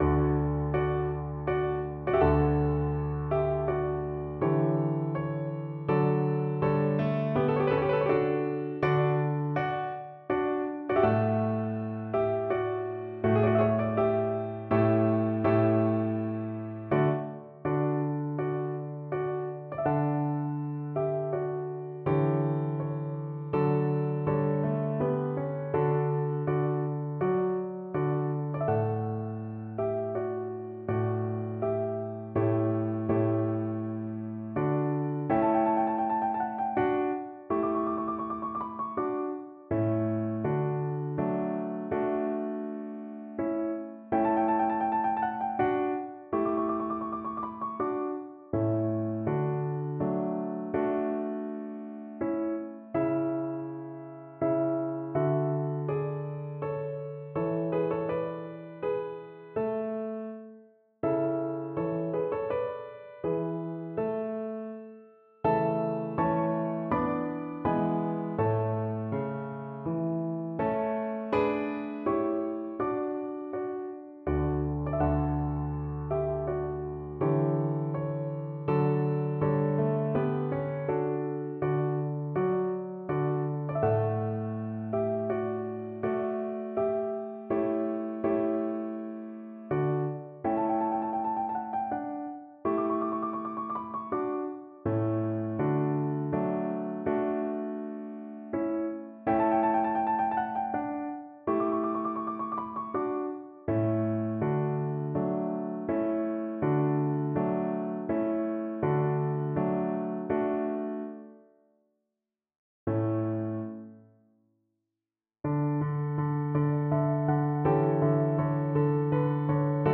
3/8 (View more 3/8 Music)
Andante =c.120
Classical (View more Classical Violin Music)